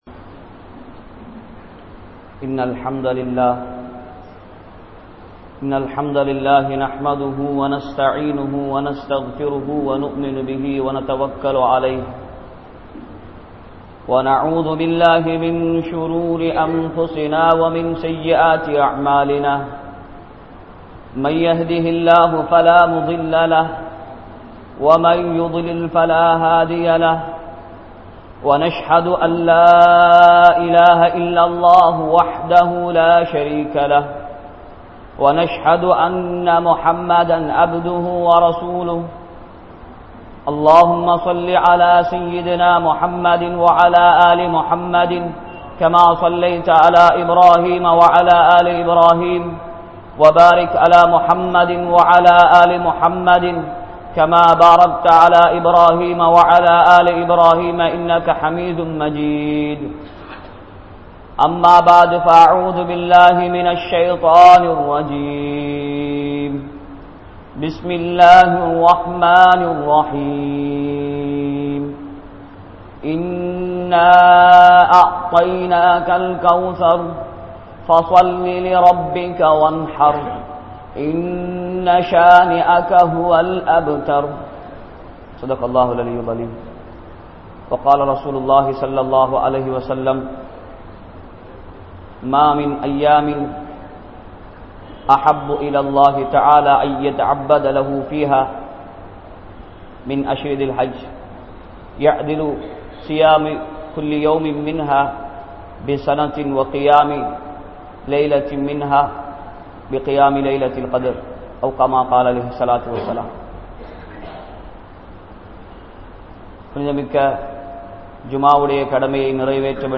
Arafa Noanpin Sirappuhal (அரபா நோன்பின் சிறப்புகள்) | Audio Bayans | All Ceylon Muslim Youth Community | Addalaichenai
Grand Jumua Masjith